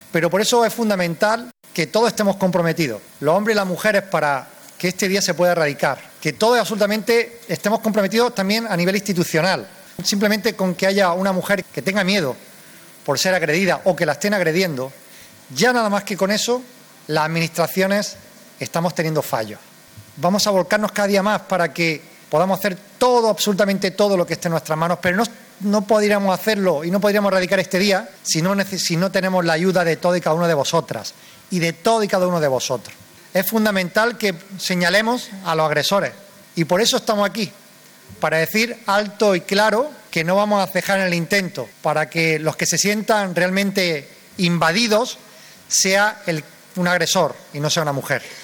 El acto central del 25N se ha celebrado en el municipio roquetero con una lectura del manifiesto, un minuto de silencio, una marcha silenciosa, un encendido de velas y una suelta de globos en memoria de cada víctima asesinada